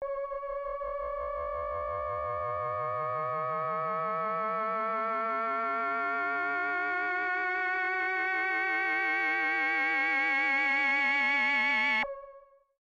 标签： MIDI-速度-32 CSharp6 MIDI音符-85 赤-AX80 合成器 单票据 多重采样
声道立体声